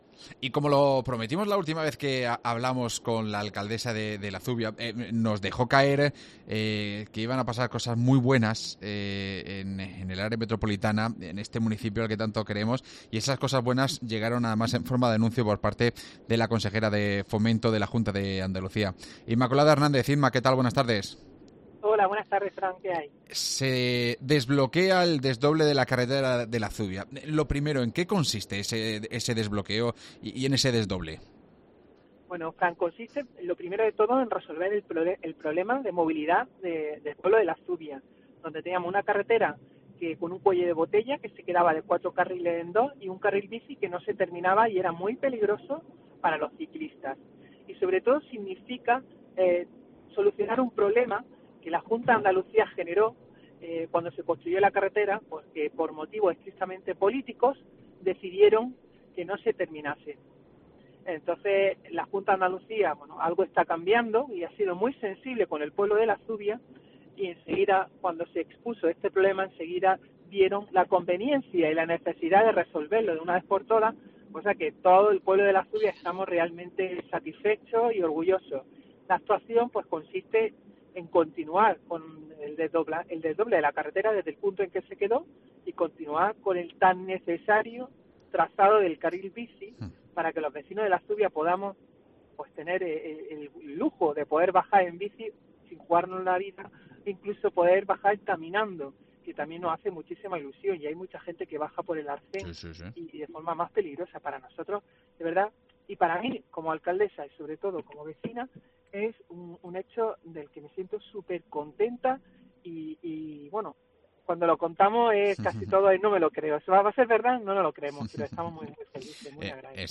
AUDIO: Hablamos con su alcaldesa Inmaculada Hernández